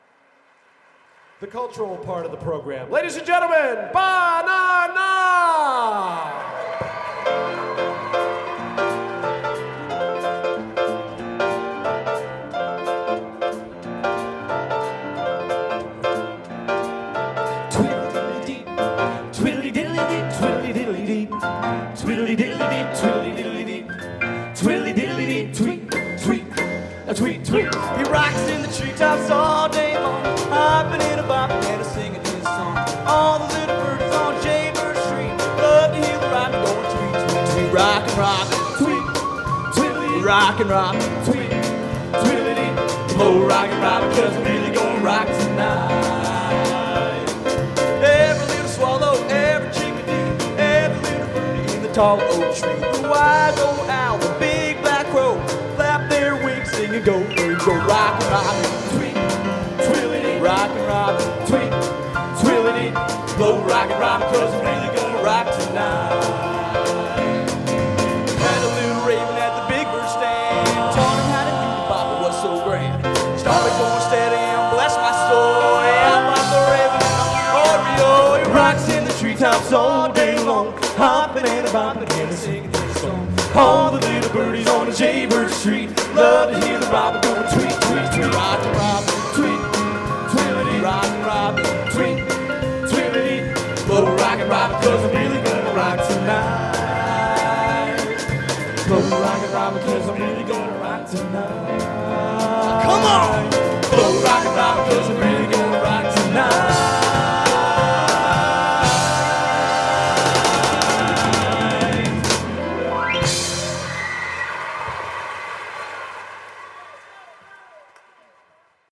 Genre: Doo Wop | Type: Specialty